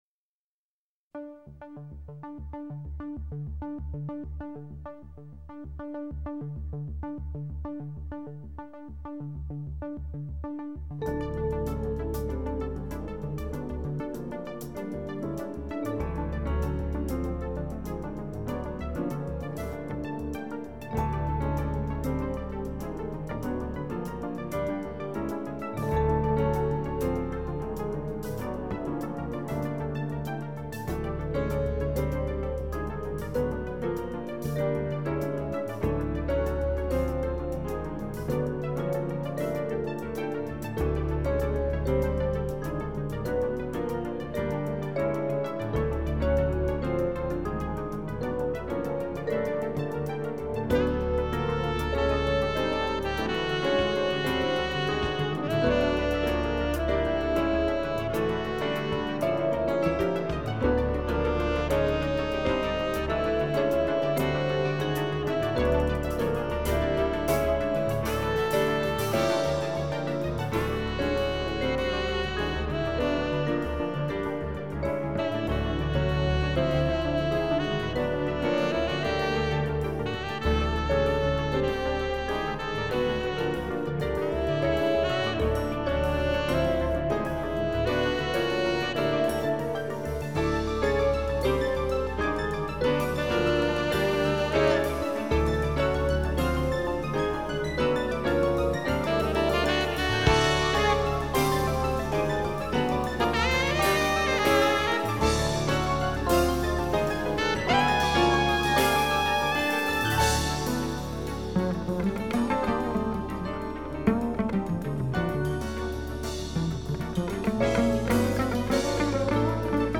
Мастеринг заценивать смысла нет, он тут грубый, лишь бы как, это грубая работа напролом, саморостом, реализуя смутную художественную абсурд-идею.